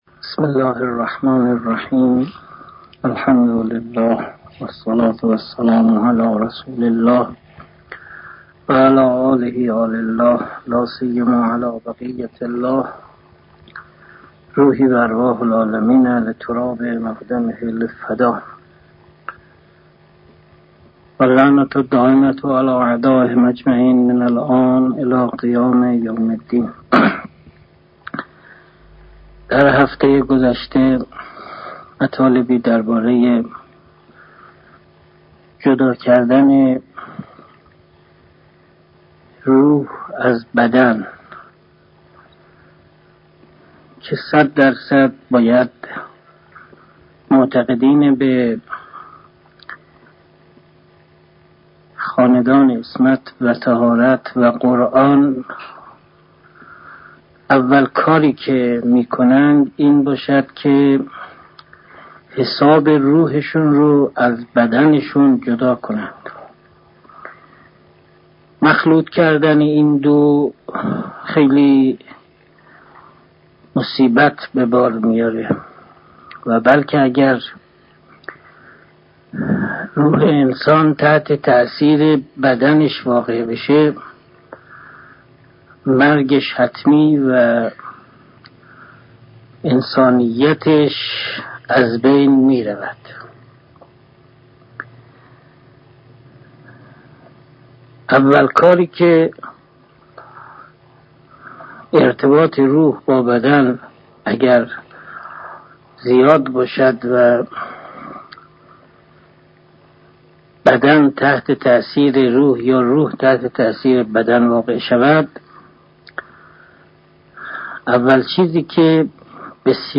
درس خصوصی غذای روح: جلسه هفتم
در این جلسه حضرت استاد در جمع سالکین الی الله درباره مبحث روح و غذای روح فرمودند که باید شما حساب روح خود را از بدن خود جدا کنید و مخلوط کردن مسایل روحی و بدنی مشکلات زیادی را به بار می‌آورد و سپس در این جلسه فرمودند: مهمترین غذای روح انسان ولایت و معرفت پروردگار می‌باشد که پنجاه درصد از غذای روح شما را تشکیل می دهد و هرکس این حقیقت را بفهمد که ولایت یعنی اطاعت از روی محبت از خدا و خاندان عصمت و طهارت (علیهم السلام) و آن را انجام دهد از ظلمت و تاریکی جهل وارد نور هدایت می‌شود و اولیاء خدا به خاط